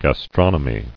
[gas·tron·o·my]